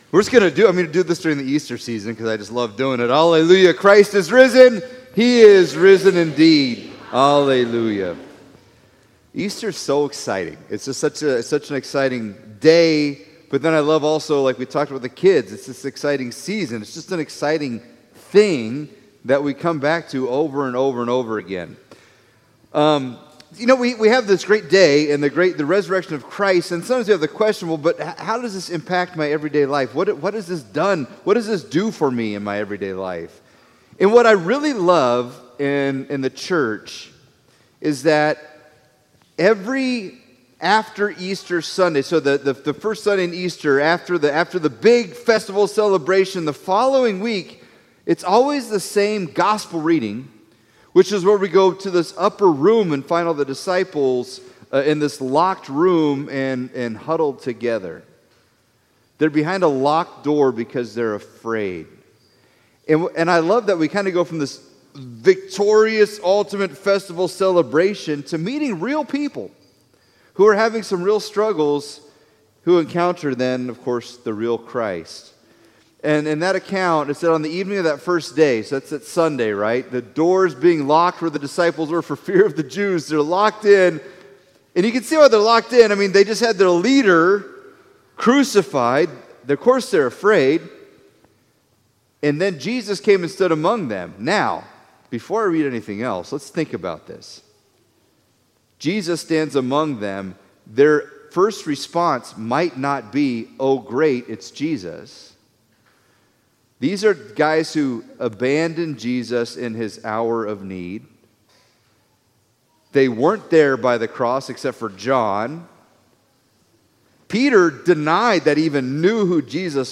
Service Type: Sunday Services